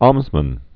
(ämzmən)